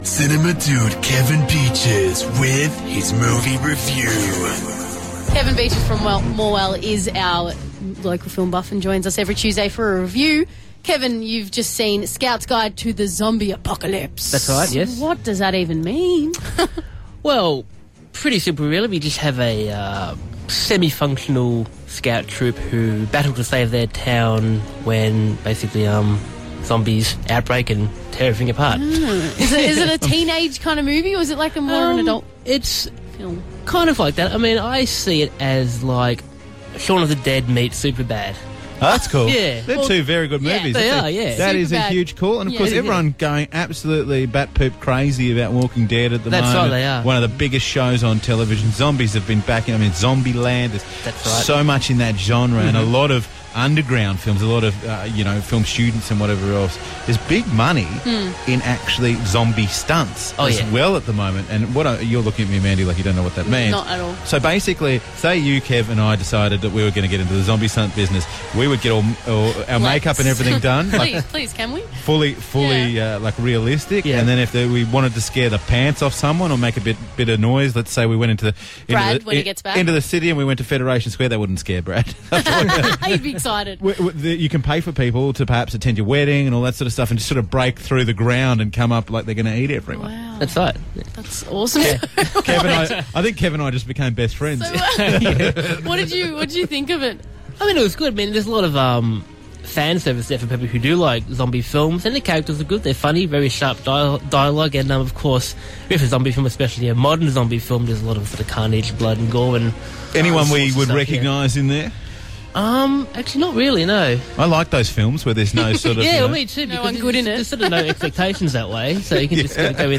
Reviewed on Star FM Gippsland